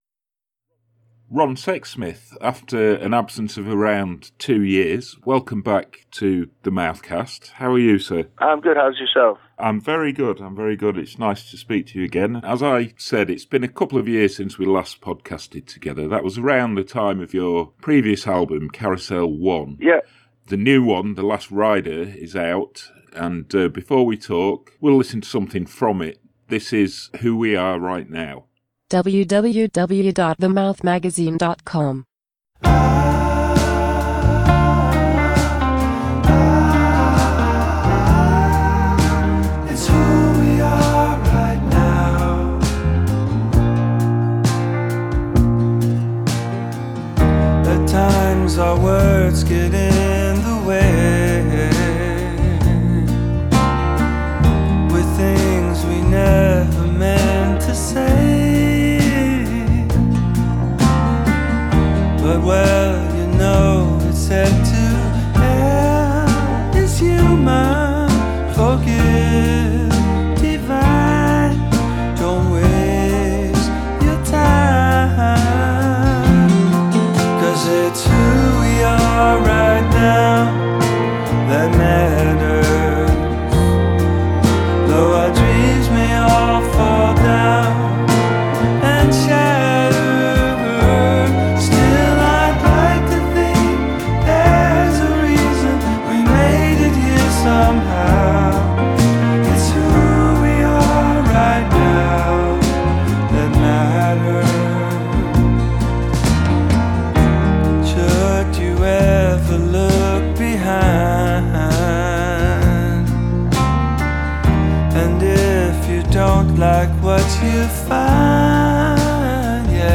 In this new edition of The Mouthcast, Sexsmith speaks on the telephone from his home, and discusses THE LAST RIDER, and reveals why some time away from music might be on the cards…